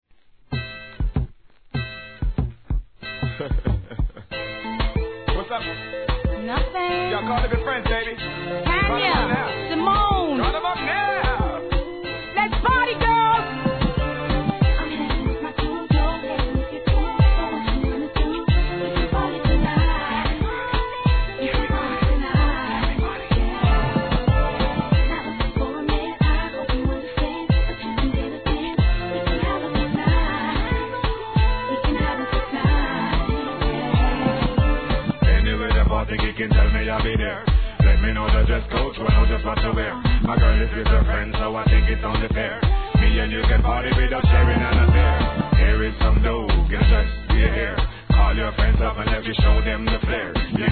REGGAE
DANCEHALL